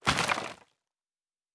Wood 07.wav